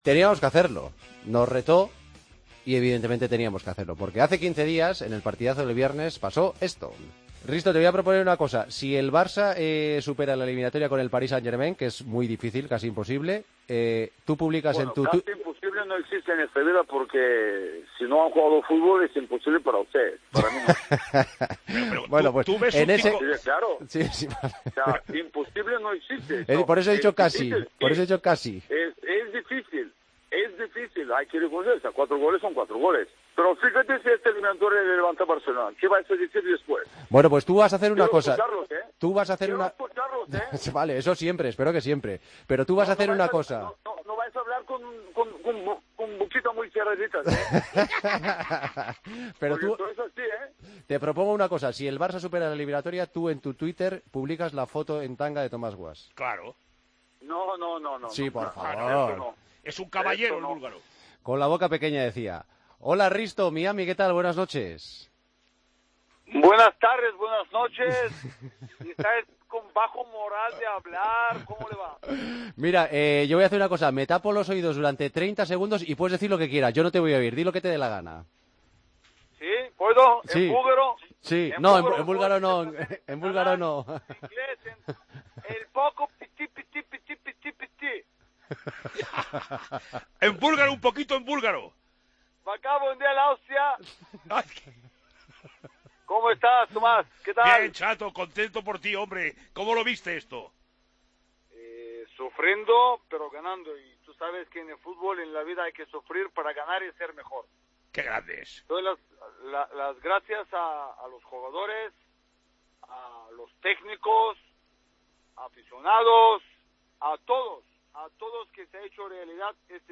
Hablamos con el exjugador del Barcelona sobre la remontada del club blaugrana y del 'Clásico' del próximo mes de julio:“Lo sufrí, pero ganamos.